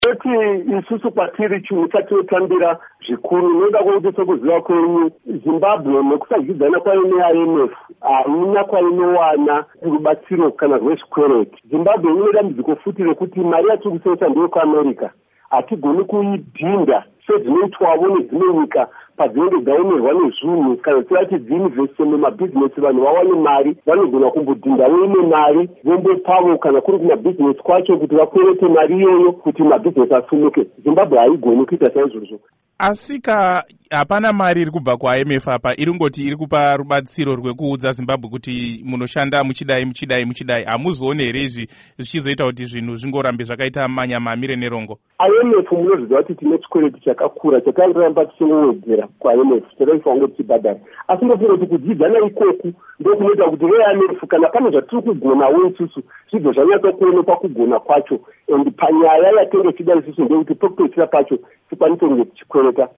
Hurukuro naVaWillas Madzimure